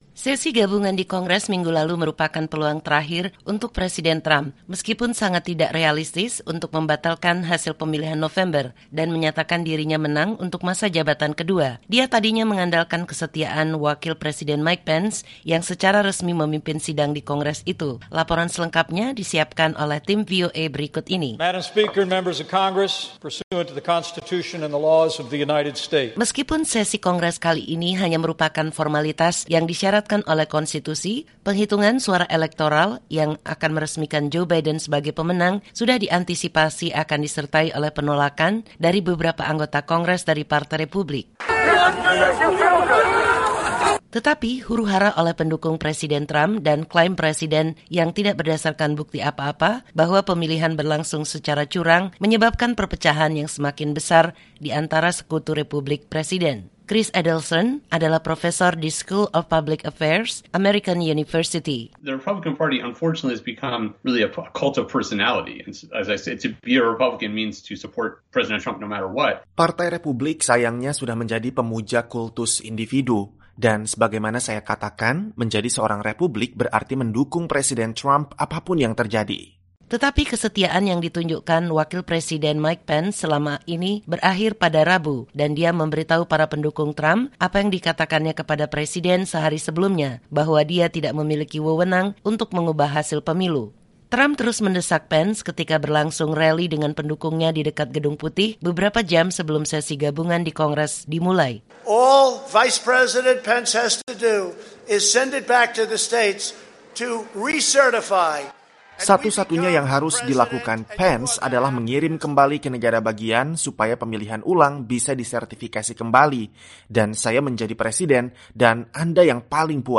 Sesi gabungan di Kongres minggu lalu merupakan peluang terakhir untuk Presiden Trump, meskipun sangat tidak realistis, untuk membatalkan hasil pemilihan November dan menyatakan dirinya menang untuk masa jabatan kedua. Laporan selengkapnya telah disiapkan oleh tim VOA berikut ini.